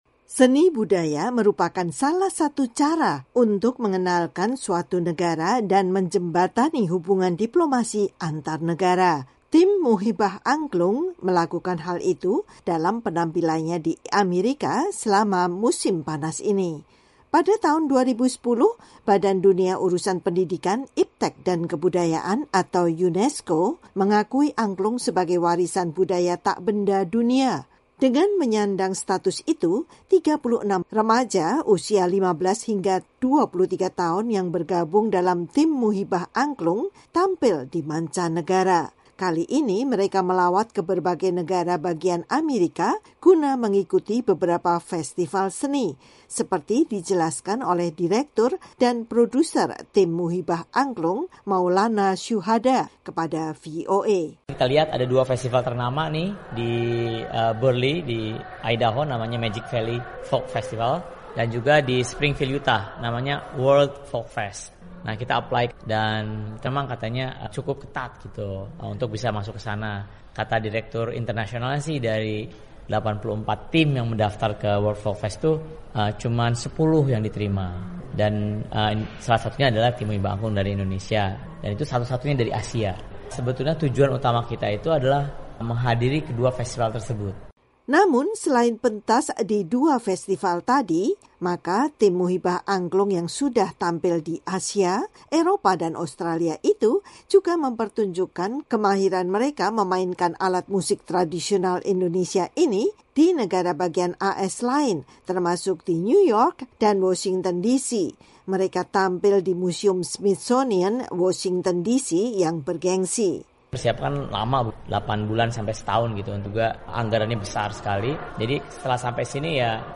Mereka tampil di museum Smithsonian, Washington, DC yang bergengsi.
Lagu-lagu yang dimainkan dengan angklung dan dinyanyikan juga beragam, mulai dari lagu Indonesia, lagu berbahasa Inggris seperti Mama Mia, dan lagu Italia klasik yang tenar seperti Volare dan O Sole Mio.